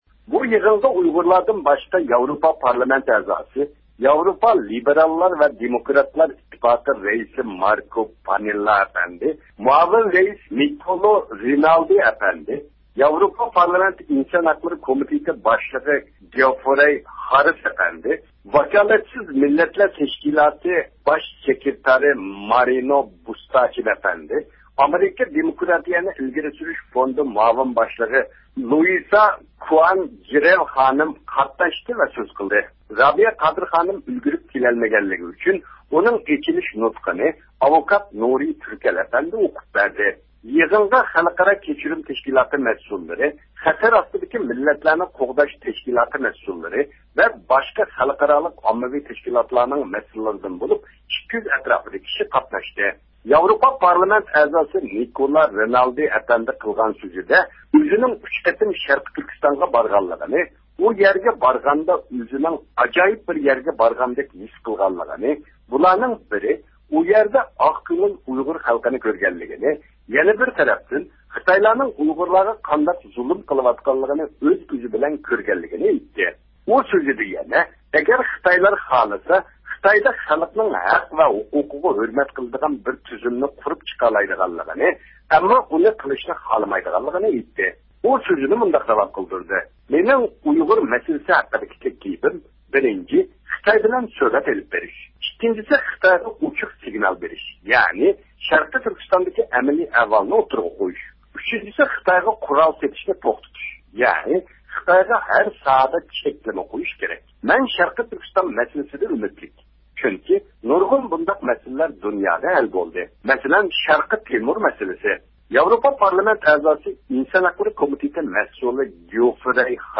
ياۋروپا پارلامېنتىدا باشلانغان خەلقئارالىق ئۇيغۇر مەسىلىسى مۇھاكىمە يىغىنىنىڭ 4 - ئاينىڭ 29 - كۈنىدىكى قىسمىنىڭ قانداق ئۆتكەنلىكى ھەققىدە، كۆز قارىشىنى ئېلىش ئۈچۈن بۇ يىغىنى ئۇيۇشتۇرغۇچى ئورگانلاردىن بىرى بولغان دۇنيا ئۇيغۇر قۇرۇلتىيى باش كاتىپى دولقۇن ئەيسا ئەپەندى بىلەن سۆھبەت ئېلىپ باردۇق.